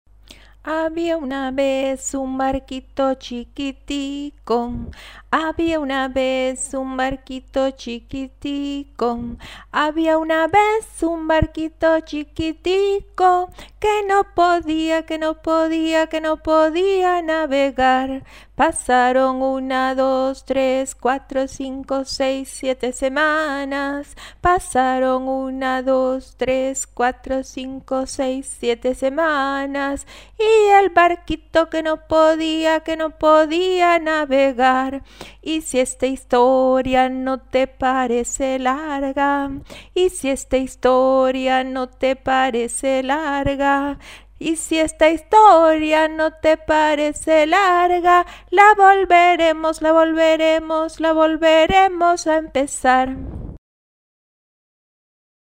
Había una vez - Chansons enfantines cubaines - Cuba - Mama Lisa's World en français: Comptines et chansons pour les enfants du monde entier